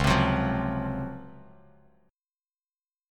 C#M11 chord